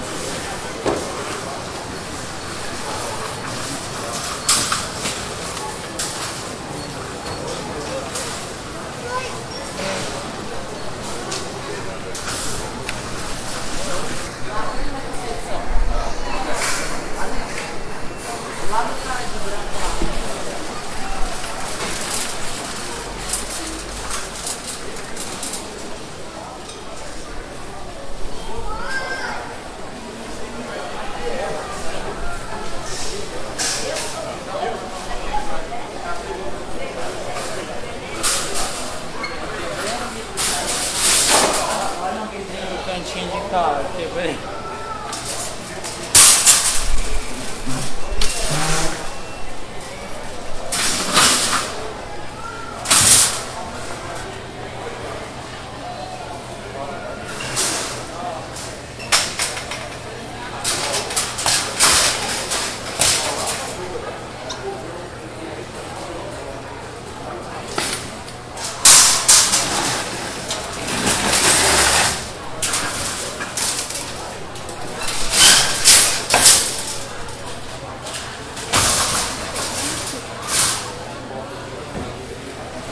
描述：超市环境
Tag: 儿童 语音 扬声器 超市 球童 人业 贸易